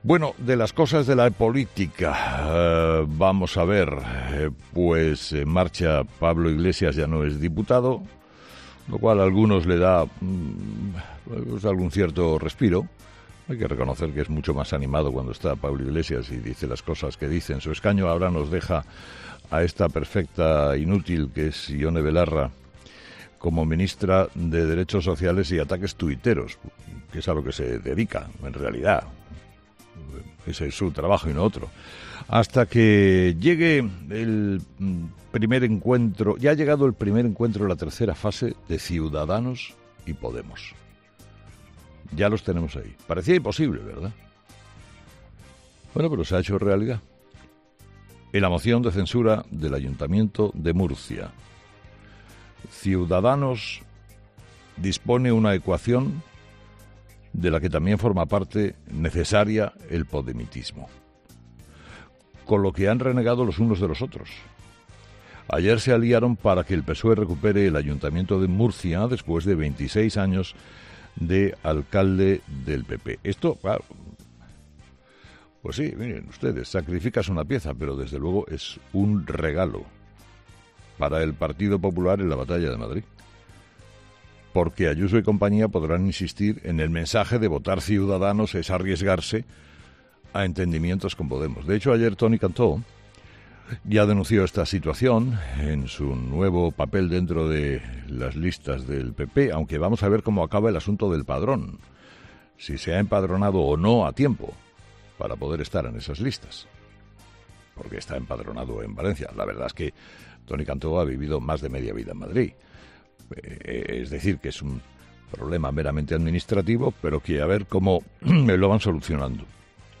Carlos Herrera analiza la actualidad de la jornada en 'Herrera en COPE'